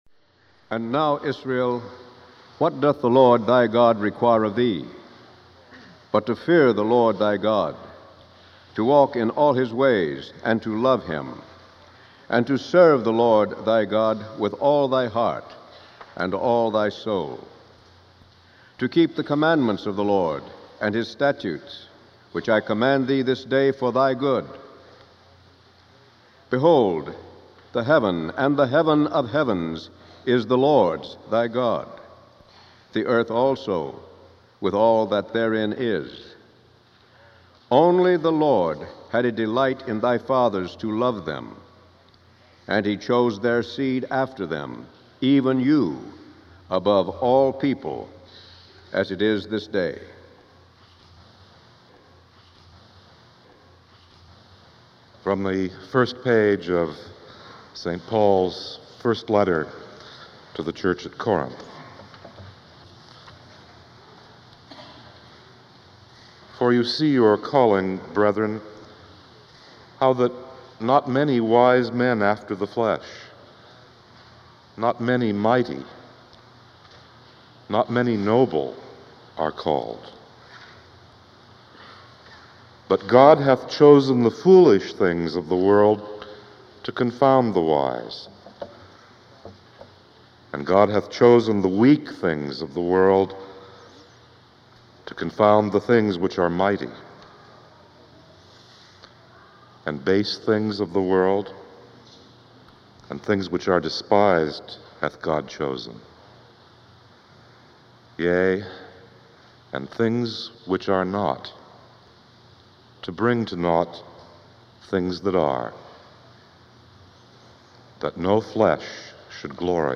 Baccalaureate Service Sermon
The short break at 9:58 is where the original casssette tape was turned over.